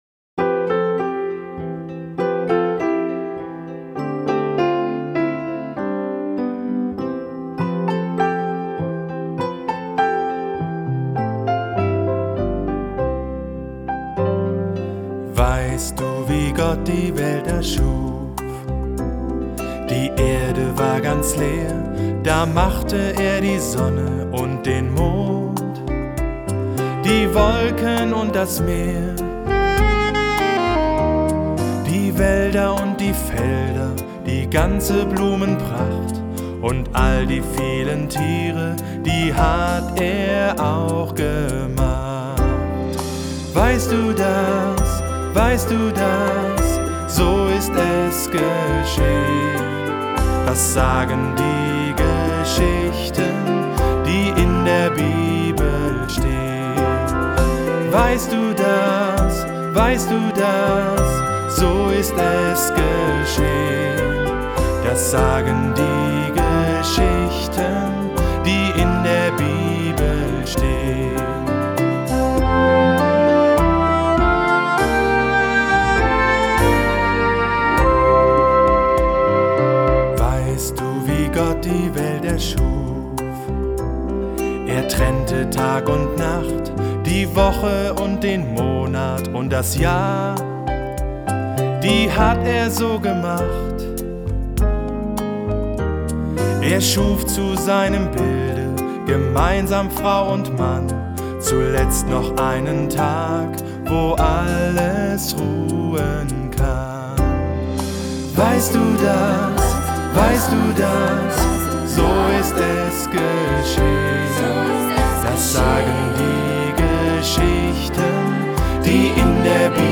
Das grosse Bibel-Hörbuch | Die Bibel